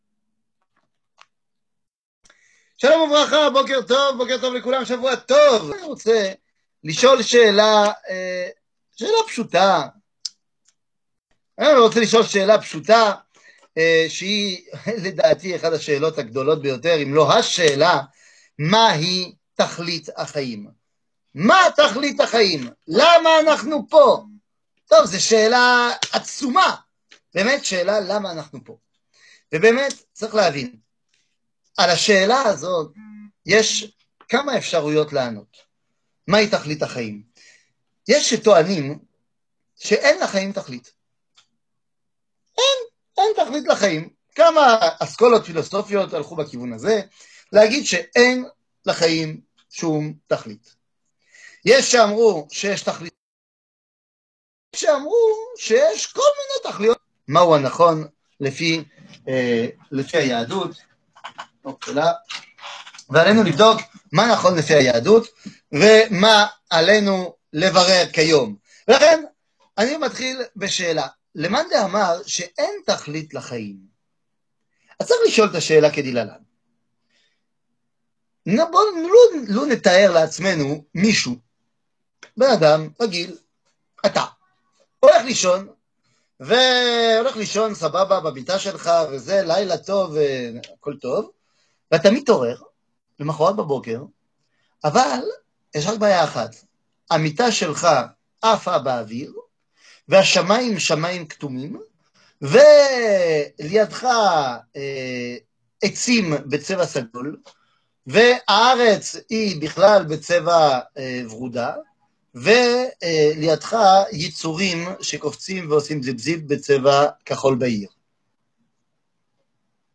מה תכלית החיים זהות שיעור מ 22 נובמבר 2020 33MIN הורדה בקובץ אודיו MP3 (30.88 Mo) הורדה בקובץ אודיו M4A (5.39 Mo) הורדה בקובץ וידאו MP4 (195.37 Mo) TAGS : עברית שיעורים תורה וזהות הישראלי שיעורים קצרים